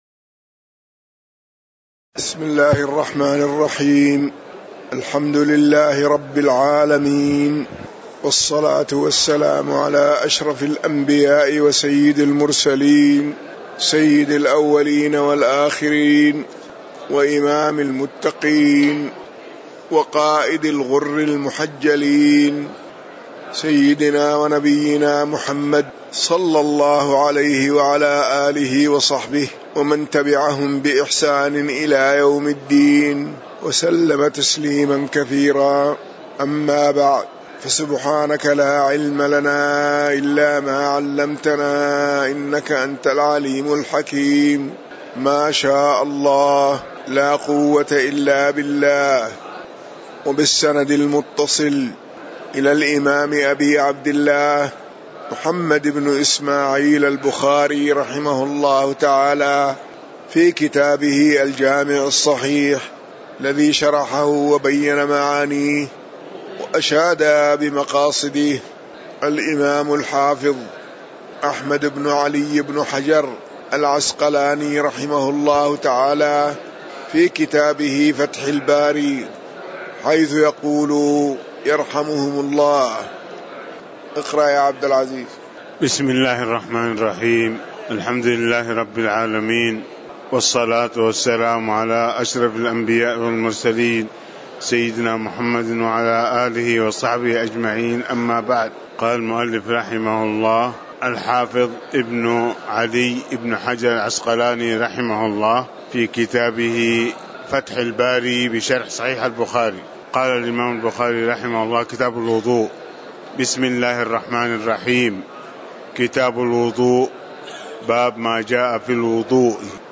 تاريخ النشر ٢٠ شوال ١٤٣٩ هـ المكان: المسجد النبوي الشيخ